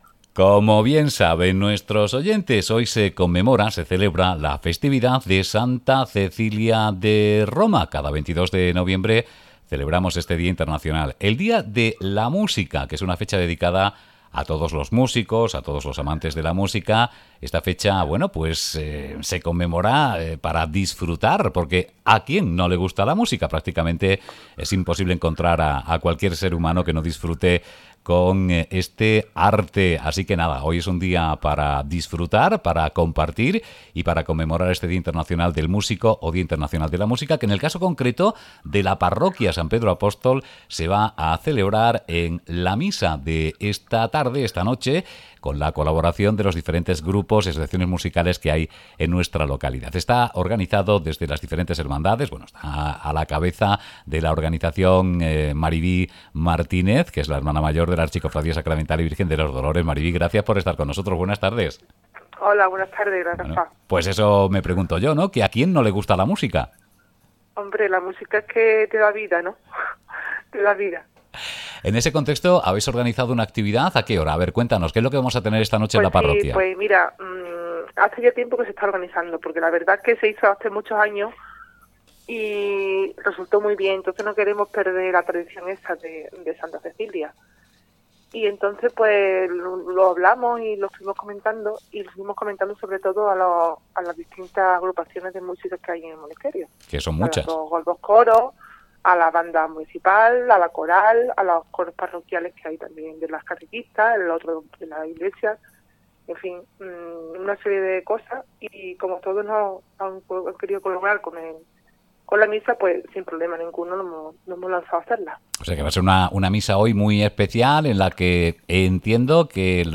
Monesterio celebra Santa Cecilia con una homilía y la actuación de 4 agrupaciones musicales